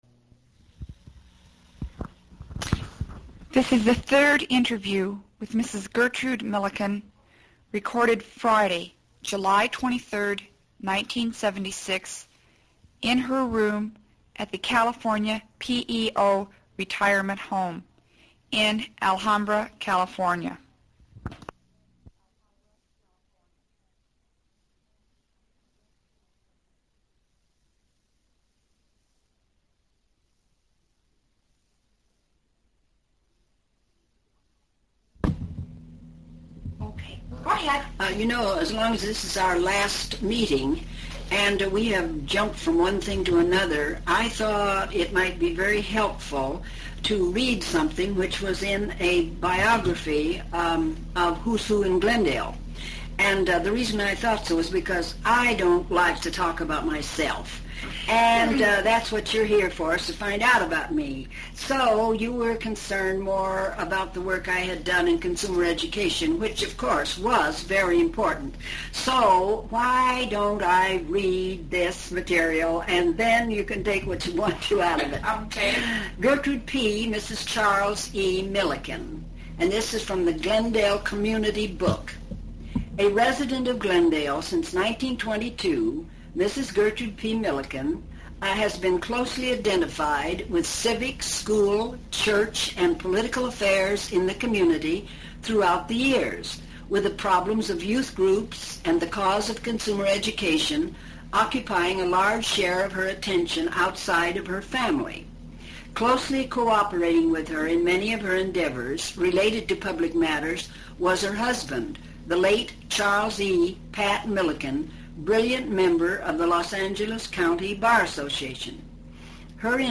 audio interview #3 of 3